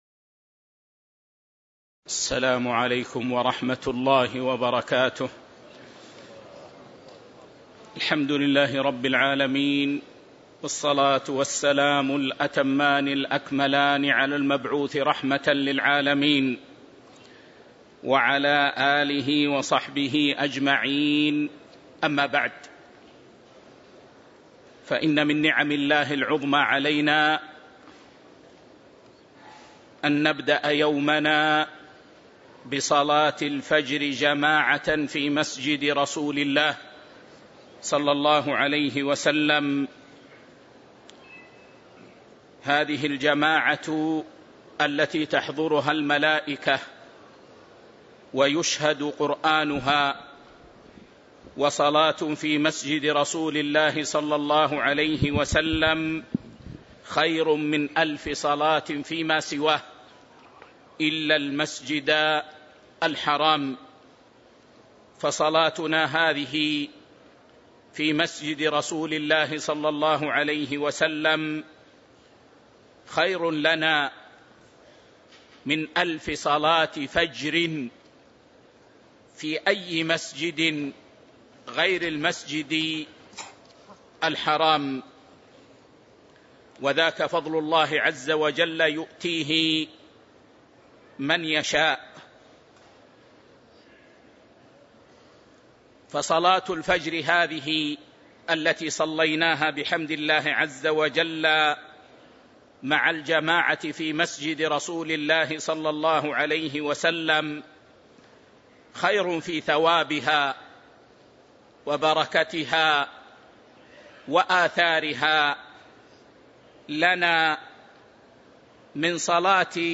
شرح تجريد التوحيد المفيد الدرس 1 المقدمة